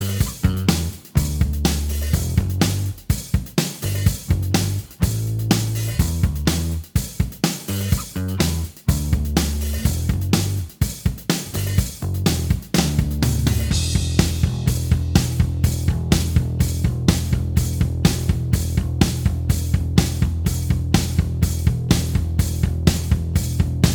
Minus Main Guitar Rock 3:26 Buy £1.50